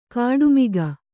♪ kāḍu miga